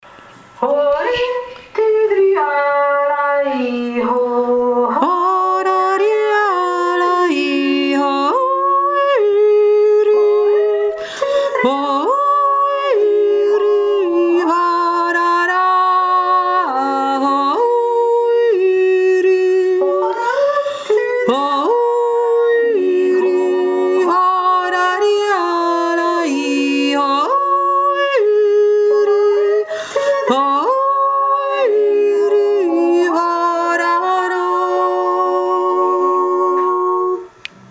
Horiti drialei ho - Jodler
3. Stimme